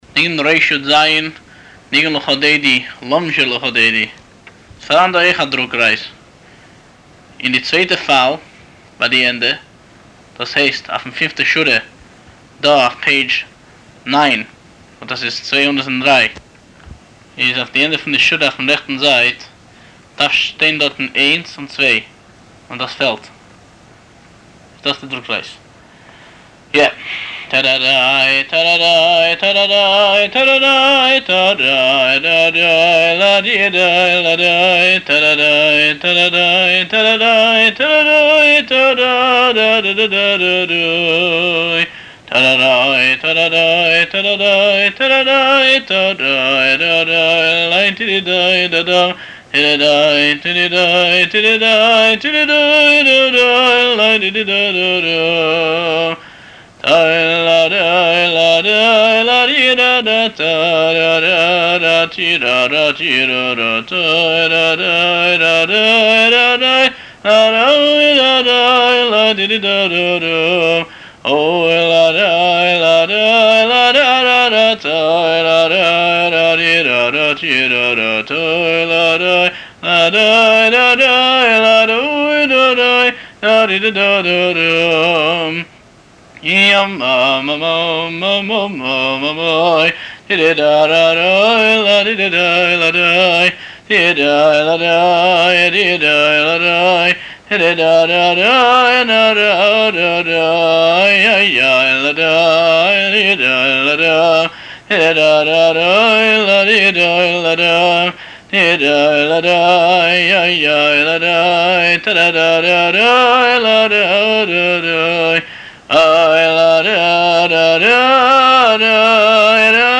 הניגון מאת הבעל-מנגן